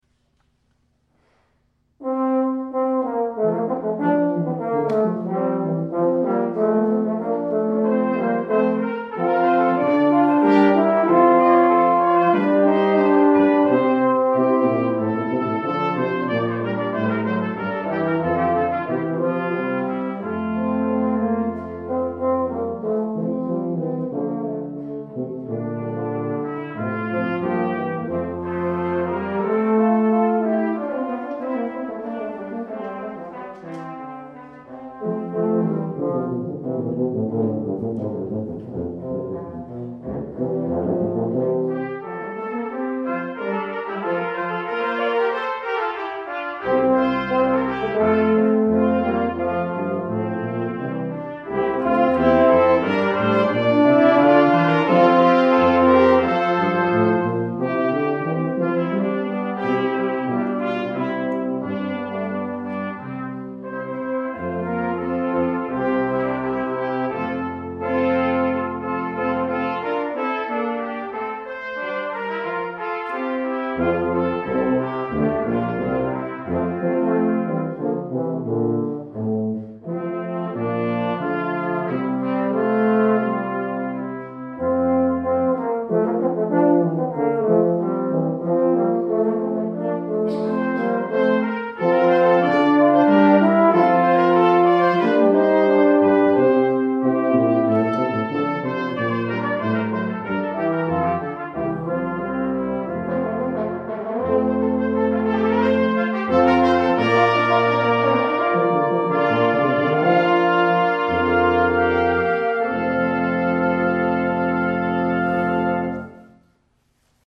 Trumpet
Horn
Euphonium
Tuba
December 2009 Recital: